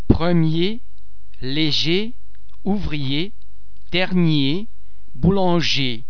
R (typical French)
the [r] is not pronounced and so the -er ending is pronounced almost as the [a] in the English word cake but much more closed.